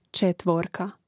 čètvōrka četvorka im. ž. (G čètvōrkē, DL čètvōrki/čètvōrci, A čètvōrku, I čètvōrkōm; mn.